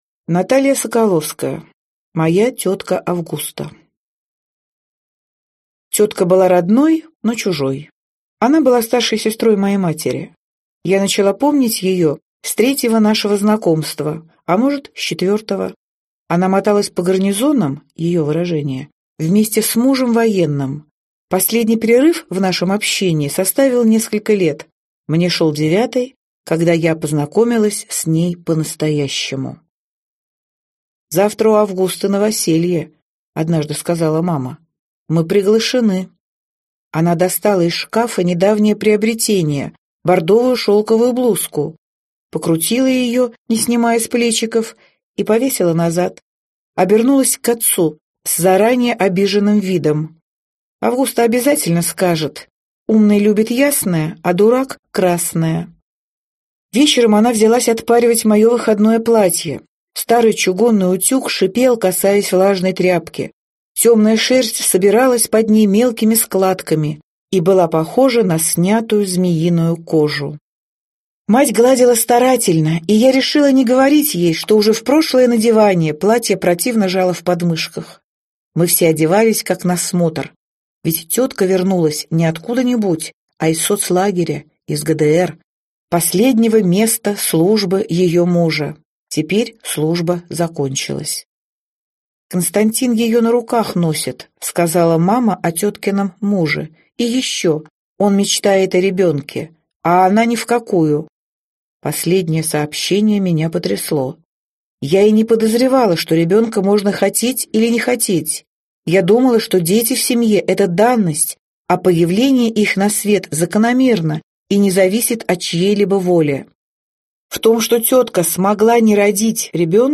Аудиокнига Моя тетка Августа. рассказ | Библиотека аудиокниг